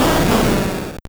Cri d'Entei dans Pokémon Or et Argent.